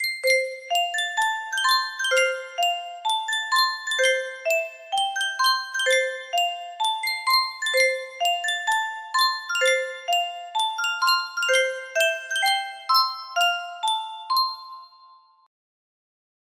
Yunsheng Music Box - Bedrich Smetana The Bartered Bride 6421 music box melody We use cookies to give you the best online experience.
BPM 128